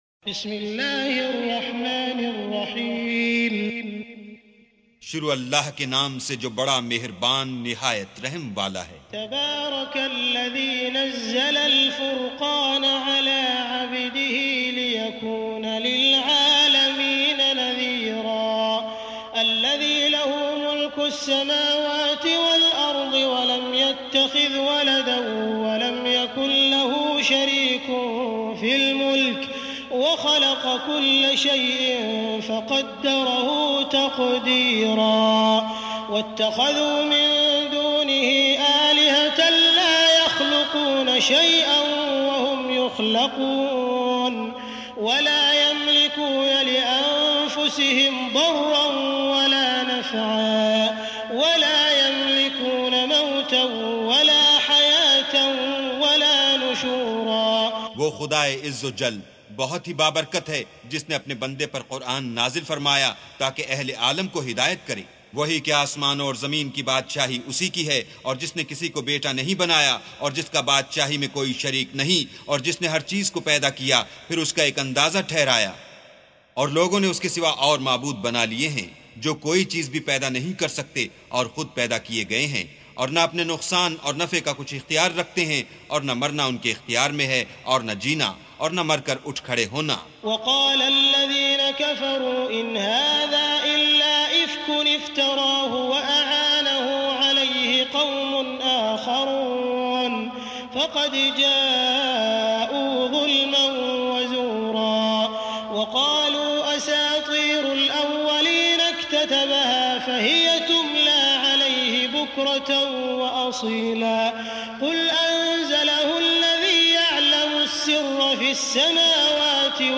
سُورَةُ الفُرۡقَانِ بصوت الشيخ السديس والشريم مترجم إلى الاردو